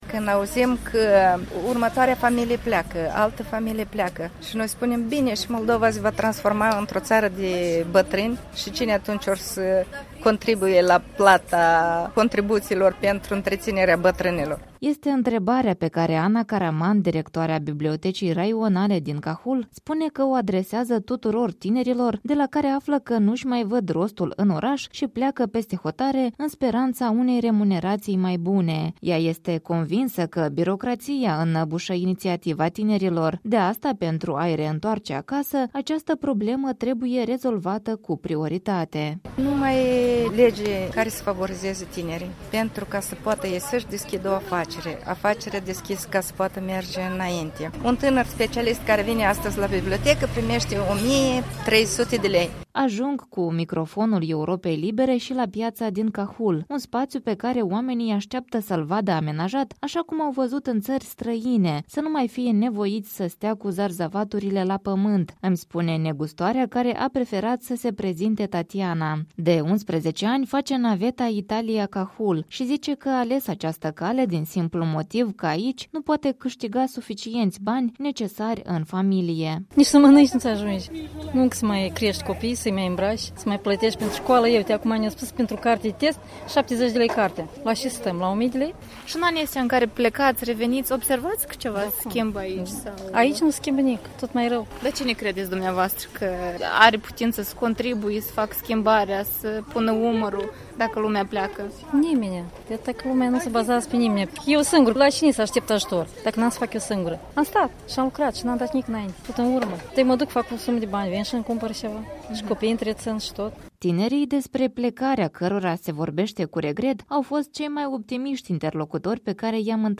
În vara lui 2015 reporterii postului de Radio Europa Liberă au făcut turul Republicii Moldova, adică au mers în peste 20 de sate şi oraşe unde au dezbătut cu localnicii problemele cu care se confruntă aceştia.